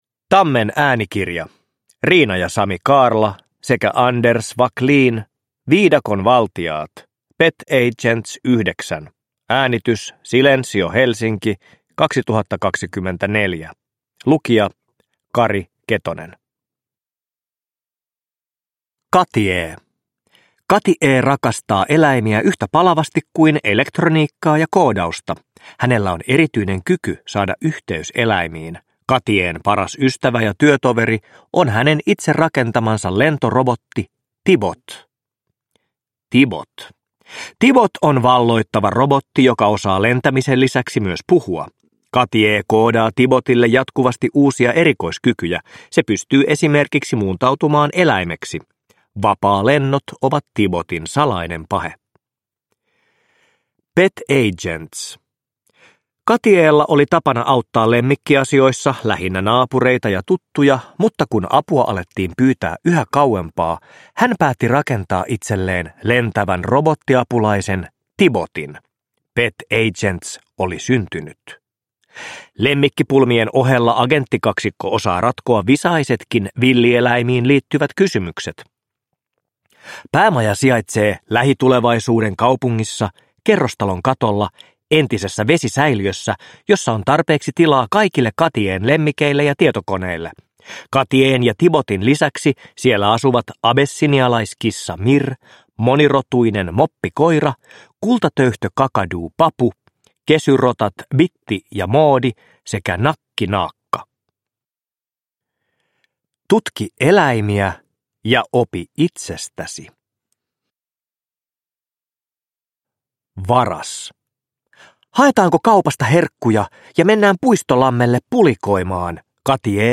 Viidakon valtiaat. Pet Agents 9 – Ljudbok